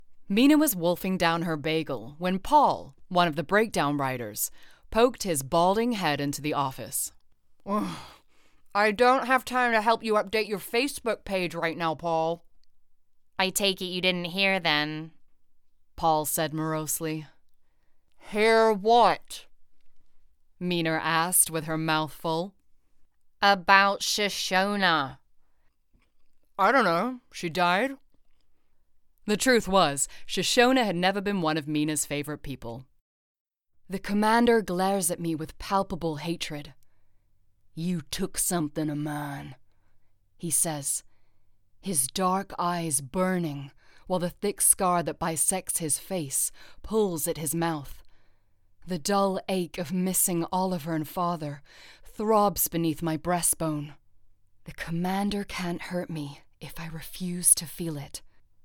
US Accent Reel
• Home Studio